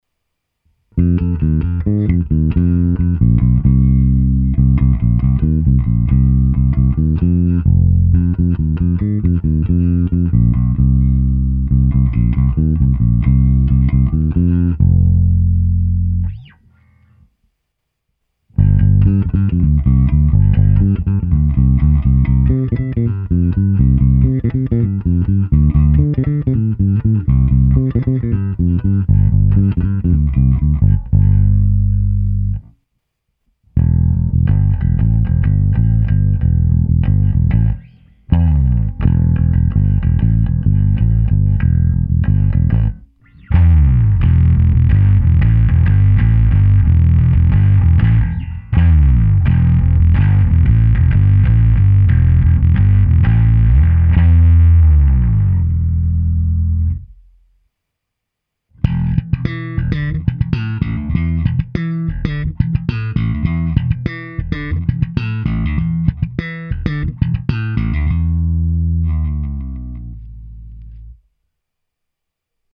Nahrávka na oba snímače sériově přes Darkglass Alpha Omega Ultra se zapnutou simulací aparátu a kompresor TC Electronic SpectraComp.